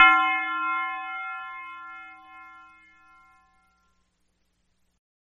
Yeat Bell Sound Button: Unblocked Meme Soundboard